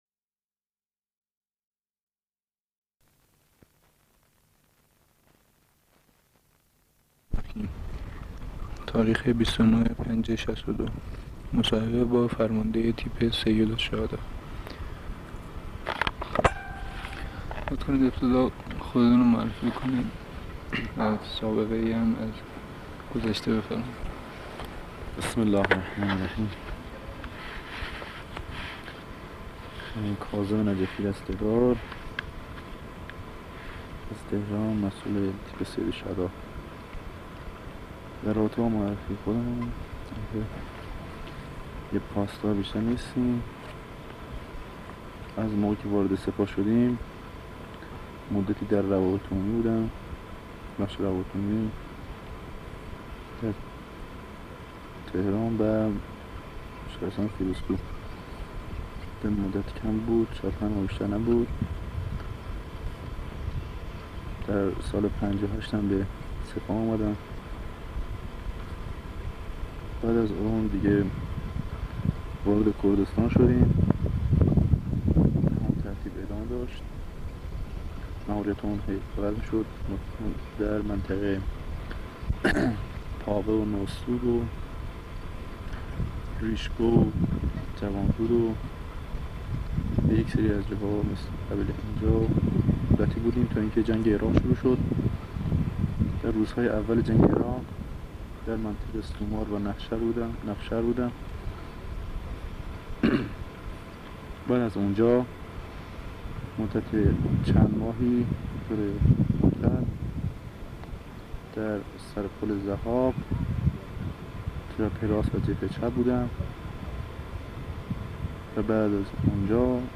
مصاحبه
در منطقه عملیاتی